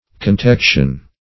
Search Result for " contection" : The Collaborative International Dictionary of English v.0.48: Contection \Con*tec"tion\ (-t[e^]k"sh[u^]n), n. [L. contegere, -tectum, to cover up.]